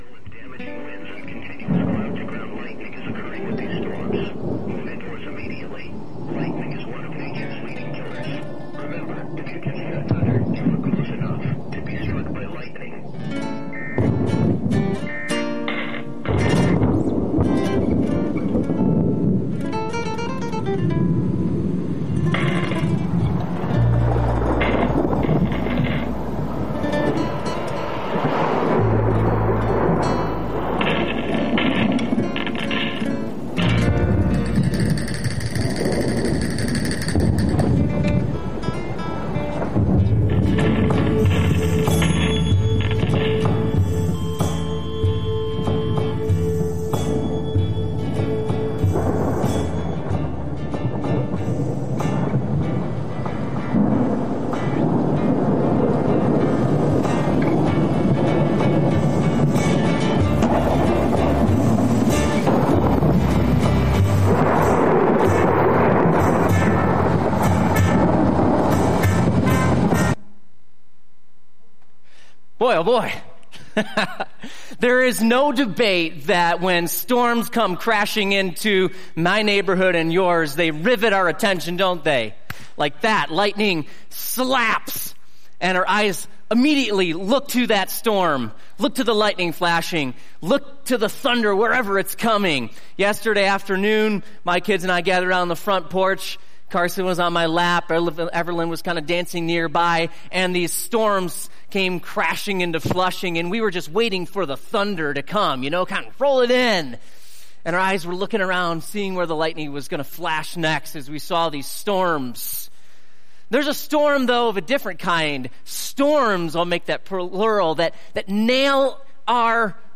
Sermons Watch the Latest Sermons on YouTube SERMONS